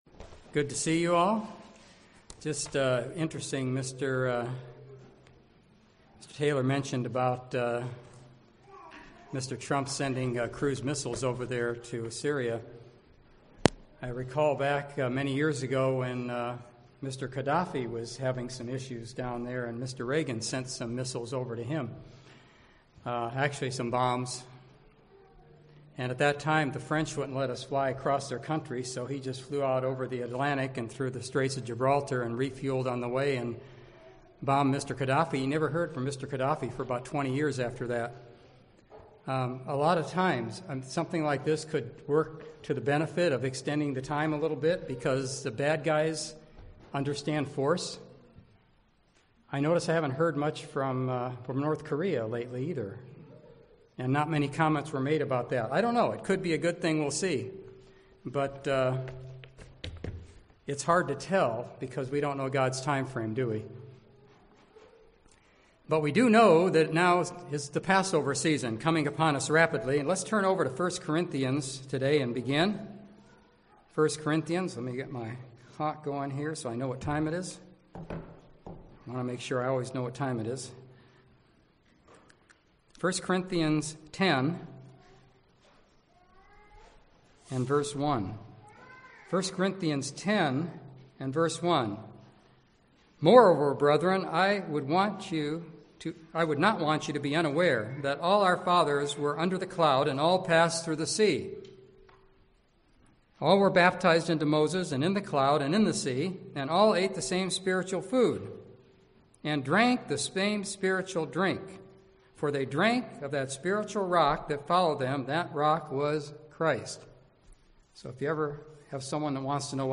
This sermon is about avoiding the bad example of ancient Israel, and the prophet Baalam the diviner by developing good spiritual habits.
Given in San Jose, CA